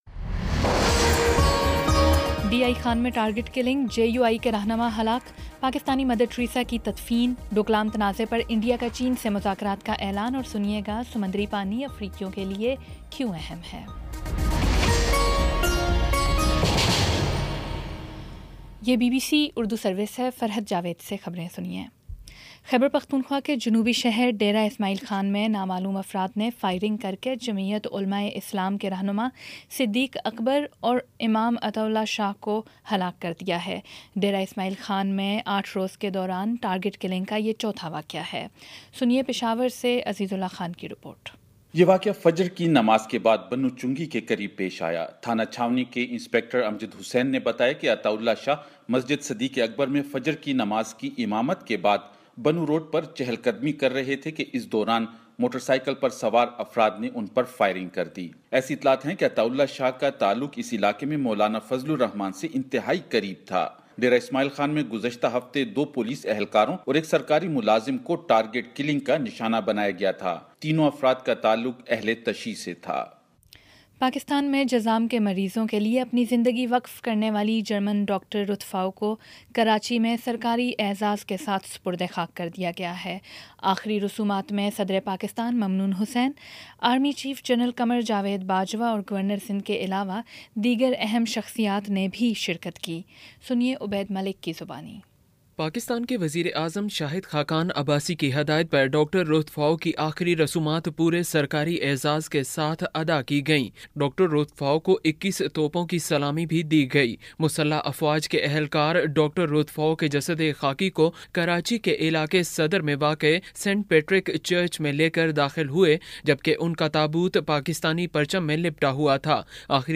اگست 19 : شام چھ بجے کا نیوز بُلیٹن